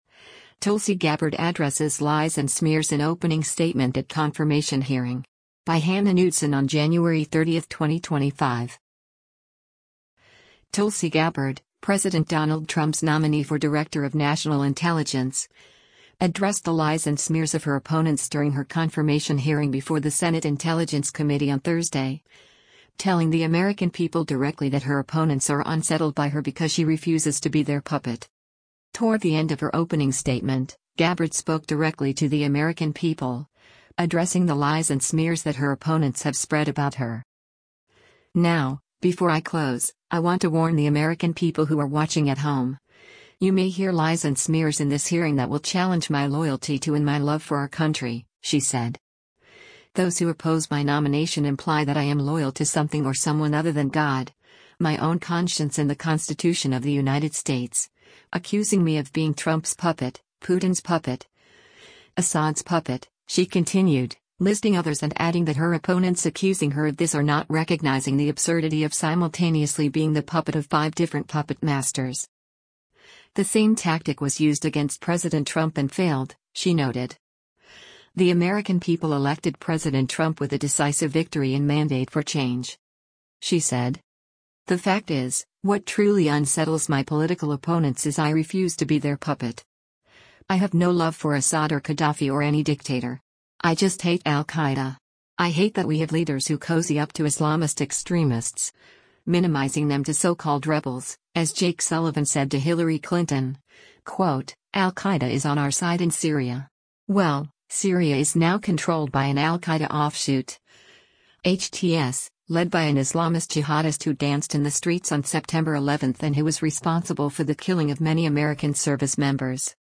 Tulsi Gabbard Addresses ‘Lies and Smears’ in Opening Statement at Confirmation Hearing